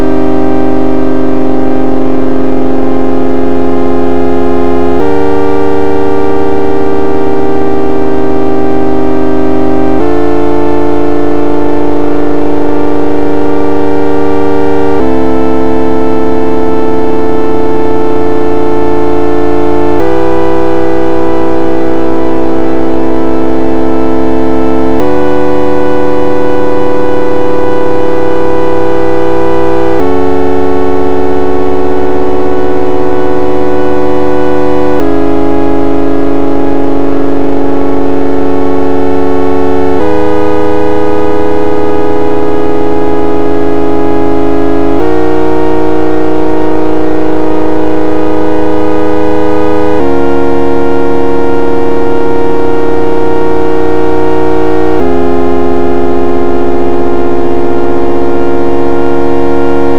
en clippant le signal (la ligne commentée), on obtient le même
avec de la distortion.